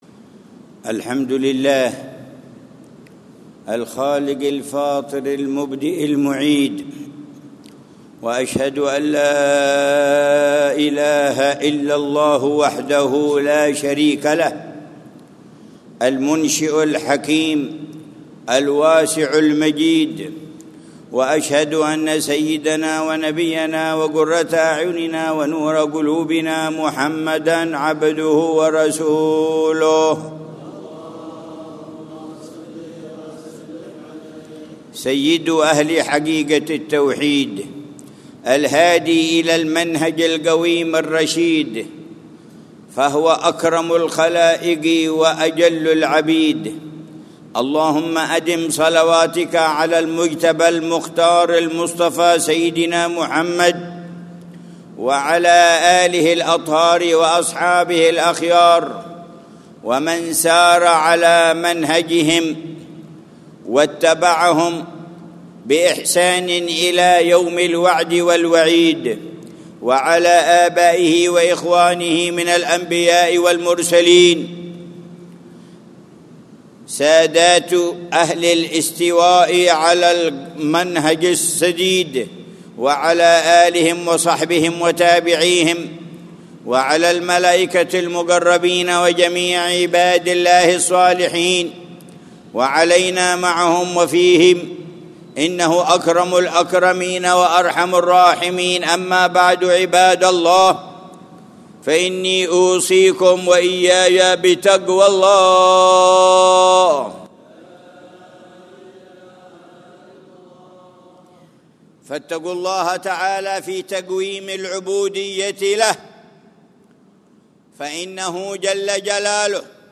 خطبة الجمعة للعلامة الحبيب عمر بن محمد بن حفيظ في جامع الإيمان، بعيديد، مدينة تريم، 10 ذو الحجة 1446هـ، بعنوان: